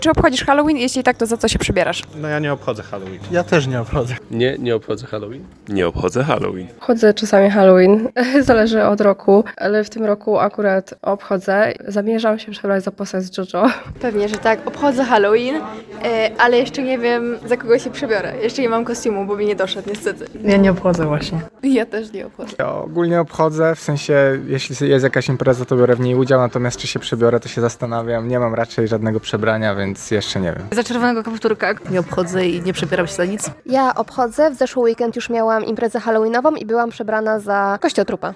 Zapytaliśmy studentów UJK, czy obchodzą Halloween i jeśli tak, to za kogo się przebierają.
Halloween-sonda.mp3